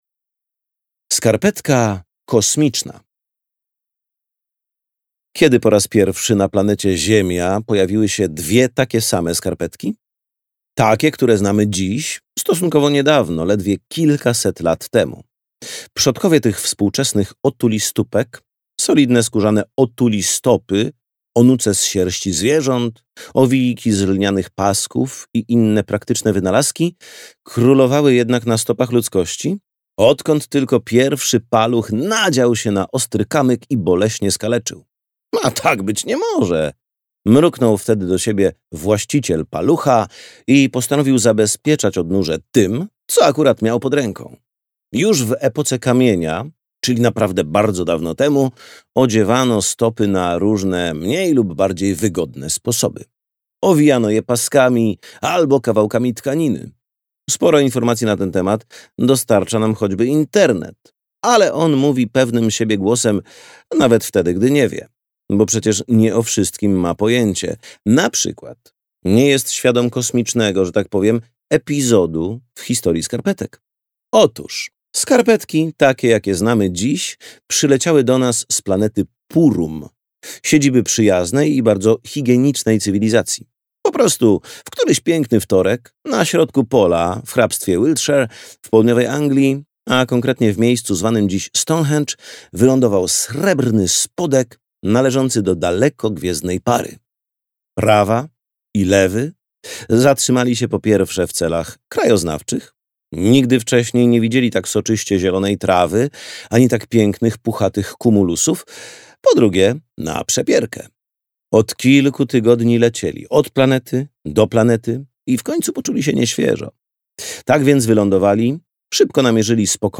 Sekretna historia skarpetek - tom.1 - Justyna Bednarek - audiobook